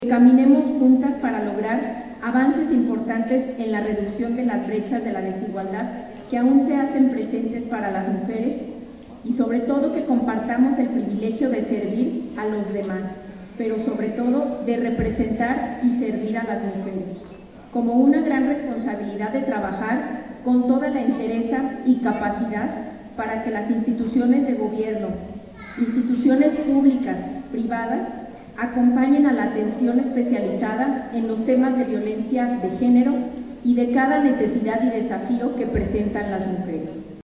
Irapuato, Gto. 25 de noviembre del 2024 .- Durante el evento ‘No estás sola’ para conmemorar el Día Internacional para la Eliminación de la Violencia contra las Mujeres, el Instituto Municipal de las Mujeres Irapuatenses (Inmira) entregó distintivos a las instituciones, empresas y asociaciones civiles que se han sumado a las estrategias municipales para combatir la violencia.
Liz Alejandra Esparza Frausto, secretaria de Derechos Humanos